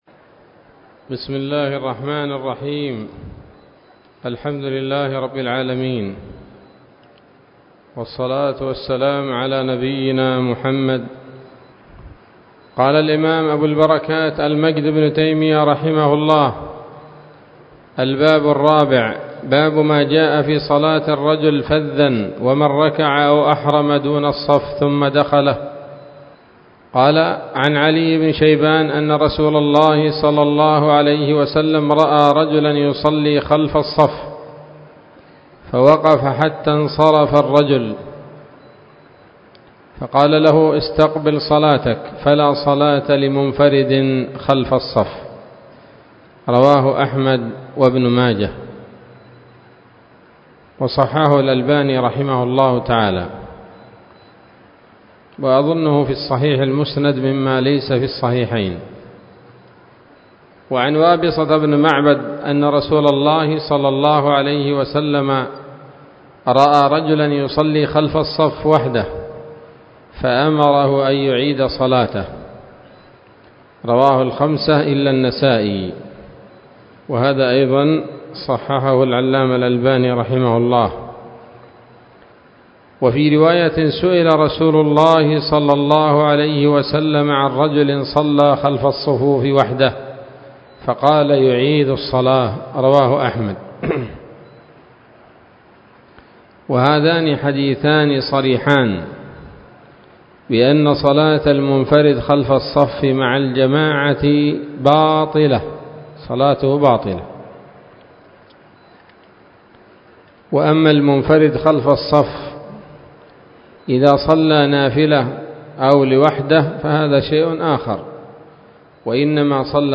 الدرس الرابع من ‌‌‌‌‌‌أَبْوَاب مَوْقِف الْإِمَام وَالْمَأْمُوم وَأَحْكَام الصُّفُوف من نيل الأوطار